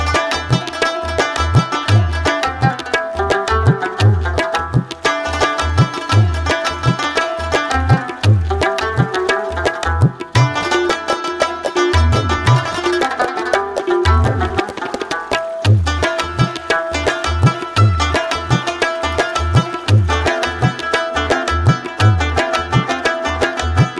Category: Tabla Ringtones